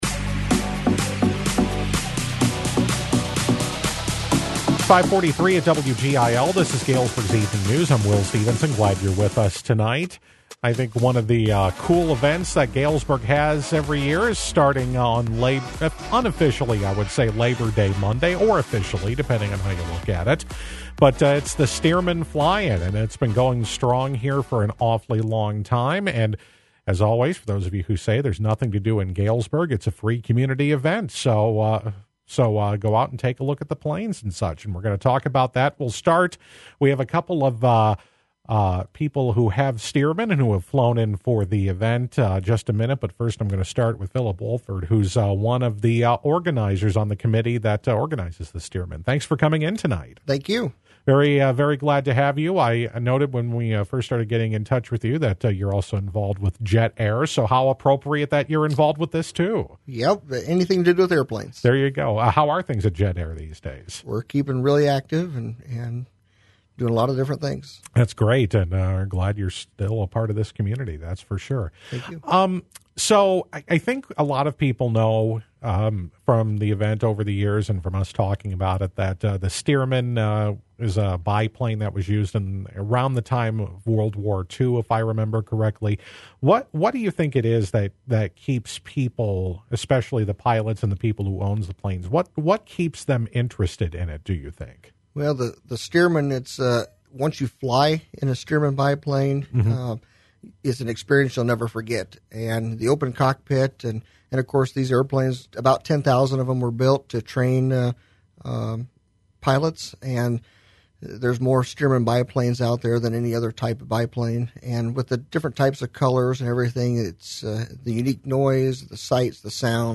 Some pilots are already in town and will be offering rides this weekend, but the annual National Stearman Fly-In officially starts next week at the Galesburg Municipal Airport! We talked with an organizer and Stearman pilots on Galesburg’s Evening News.